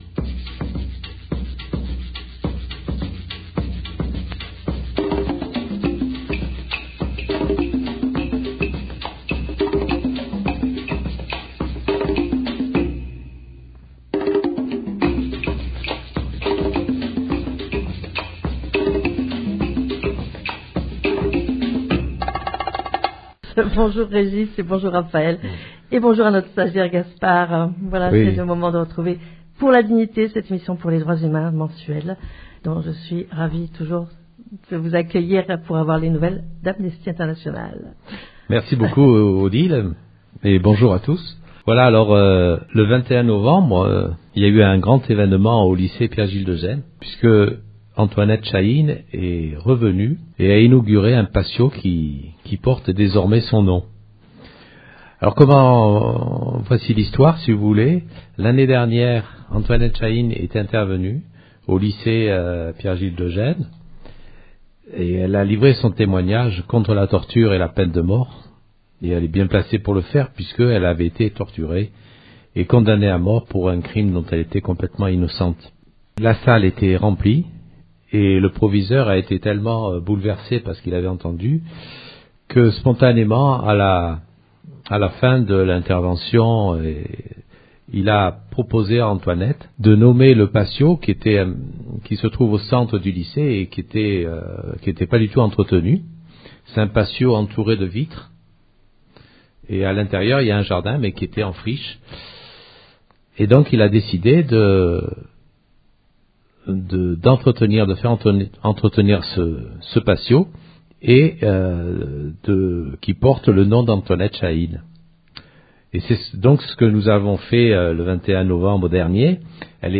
Interview de jeunes